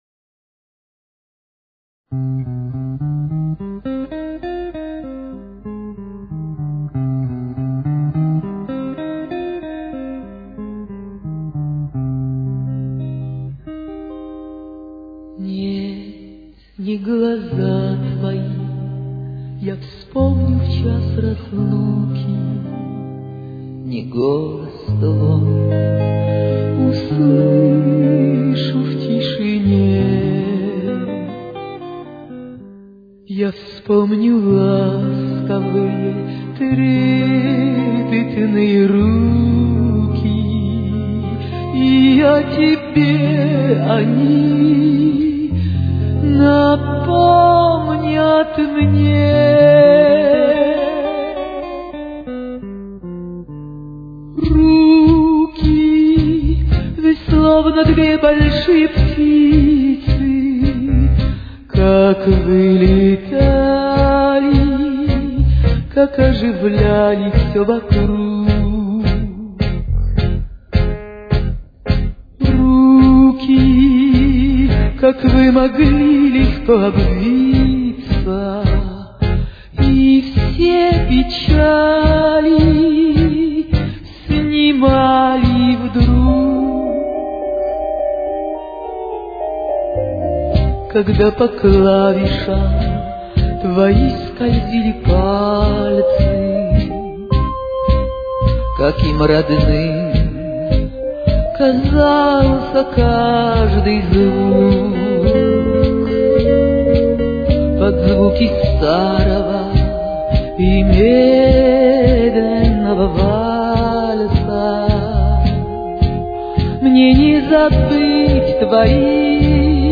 с очень низким качеством (16 – 32 кБит/с)
Темп: 54.